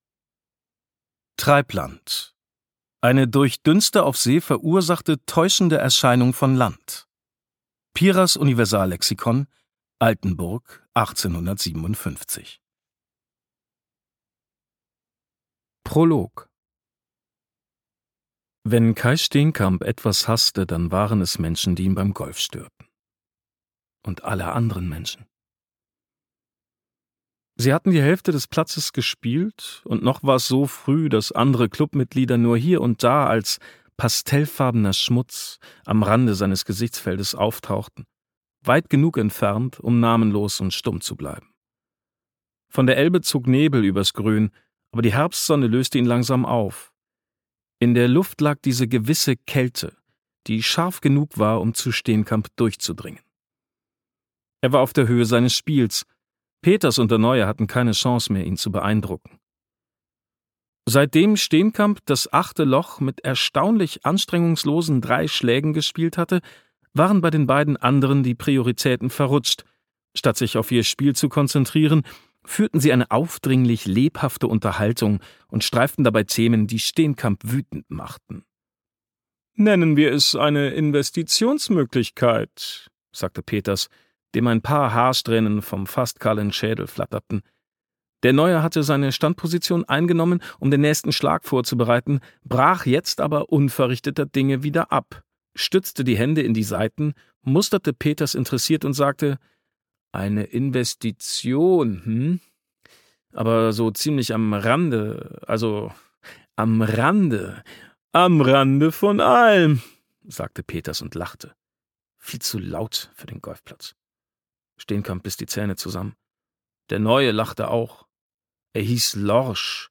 Danowski: Treibland - Till Raether | argon hörbuch
Gekürzt Autorisierte, d.h. von Autor:innen und / oder Verlagen freigegebene, bearbeitete Fassung.